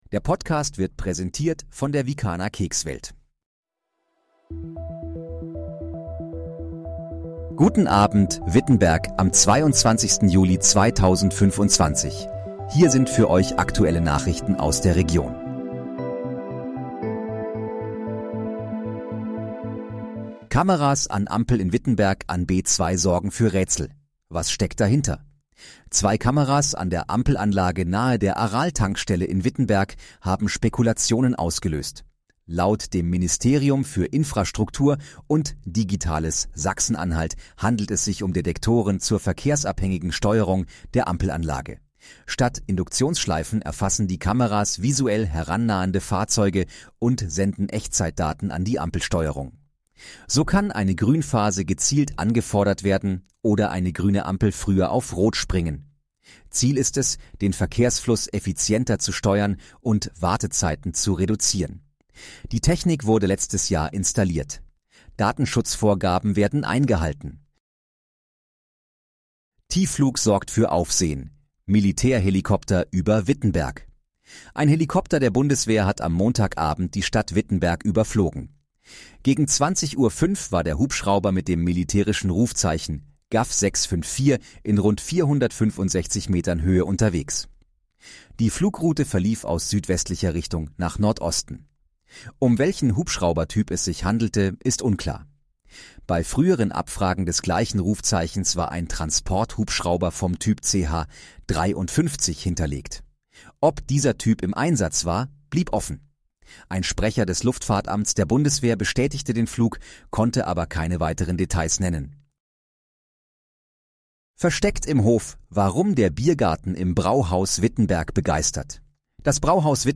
Guten Abend, Wittenberg: Aktuelle Nachrichten vom 22.07.2025, erstellt mit KI-Unterstützung
Nachrichten